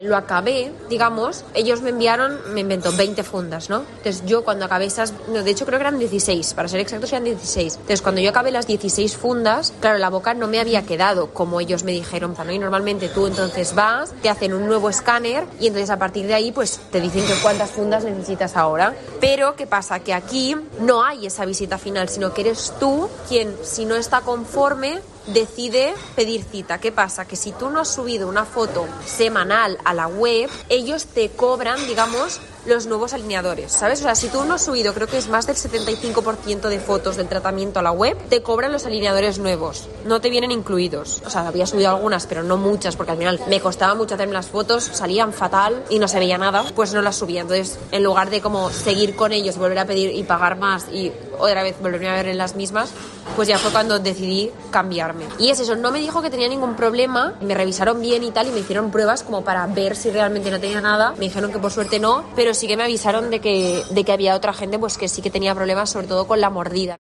paciente de este tipo de clínicas online